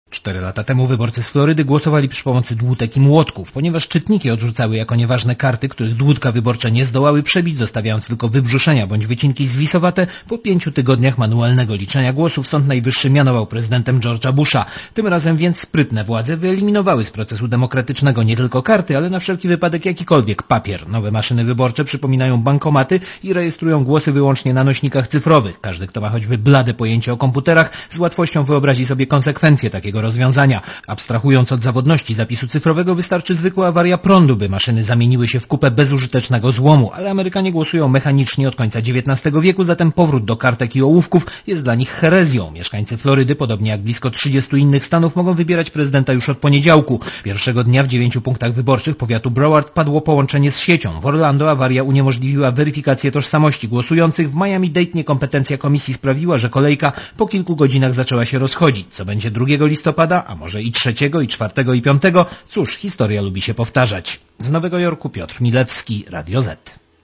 Korespondencja z USA